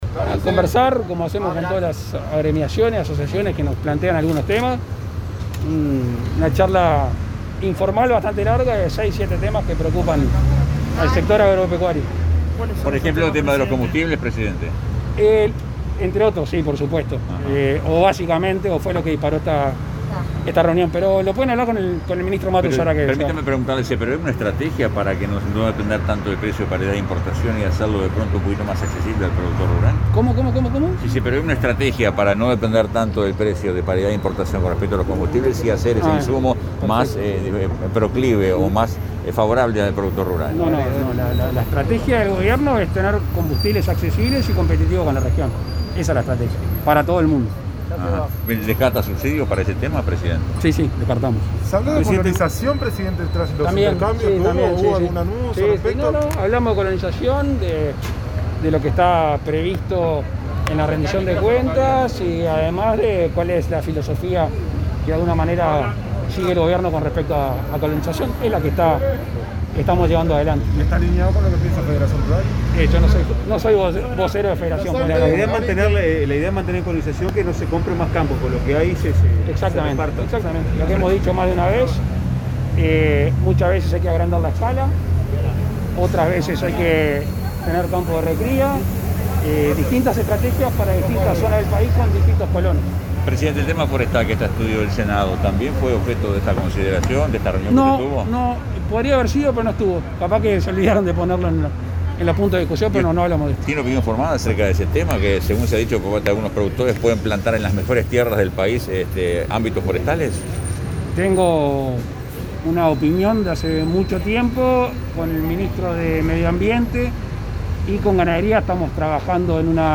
Declaraciones a la prensa del presidente de la República, Luis Lacalle Pou
Tras finalizar la reunión con autoridades de la Federación Rural, este 24 de agosto, el mandatario efectuó declaraciones a la prensa.